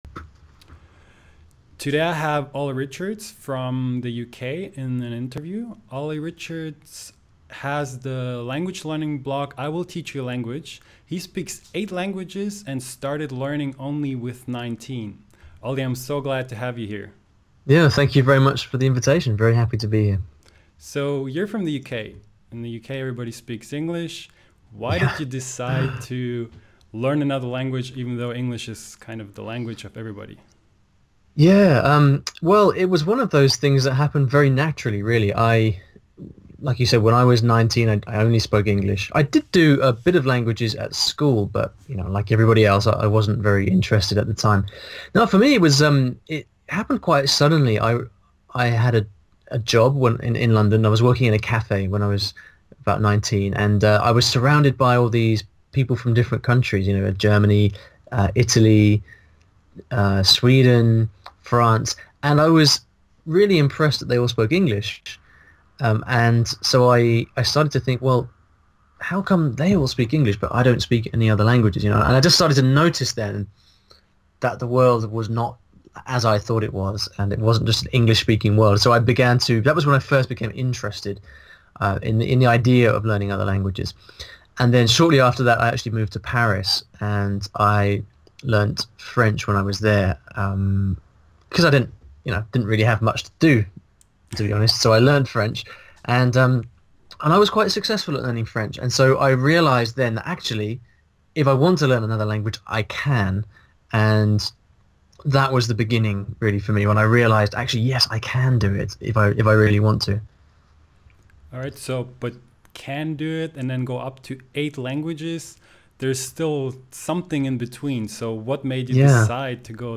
Heute habe ich einen bekannten Sprachblogger im Interview.